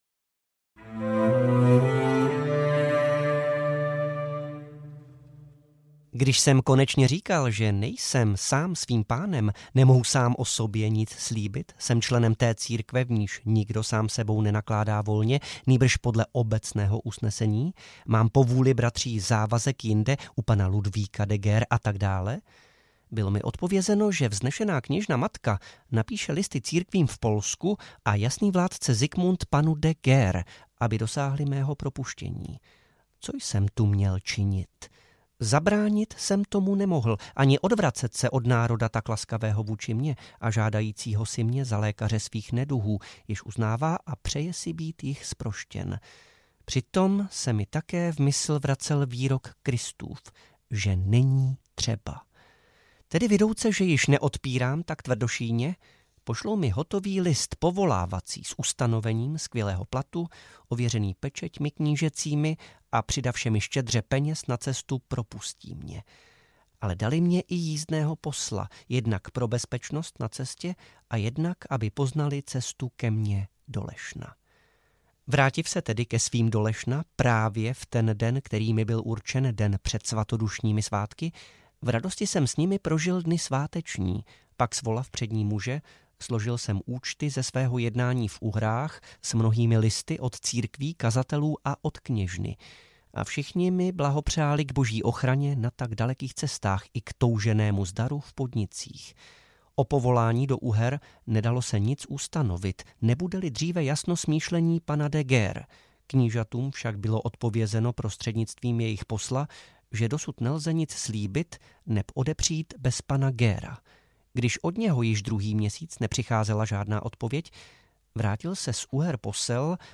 Vlastní životopis audiokniha
Ukázka z knihy